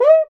PRC CUICA0GR.wav